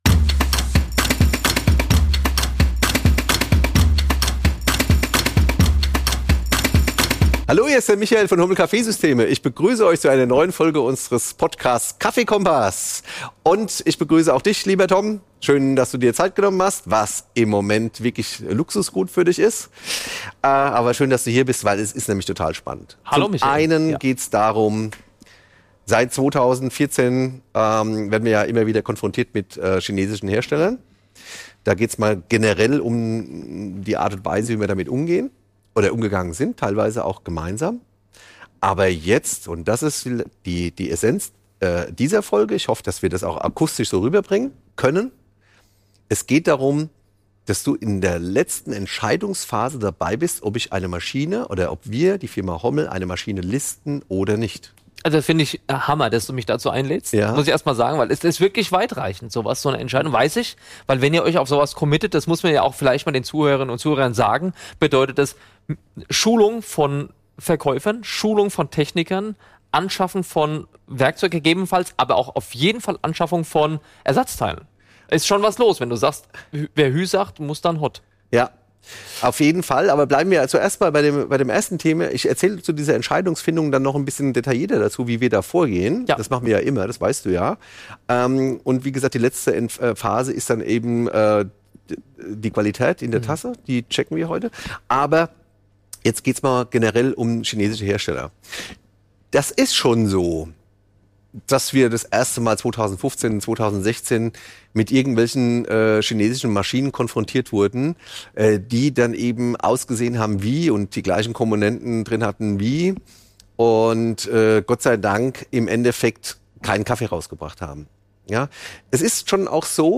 Live Test | Neu im Programm? Der Vollautomat des chinesischen Herstellers Kalerm | KaffeeKOMPASS überprüft | Folge 79 ~ KaffeeKOMPASS Podcast